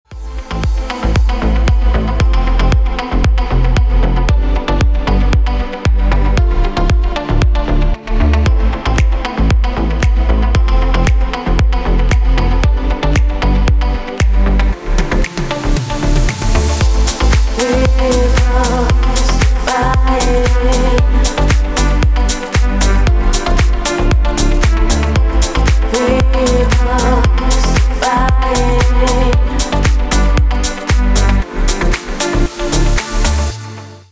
ремикс 2026 на рингтон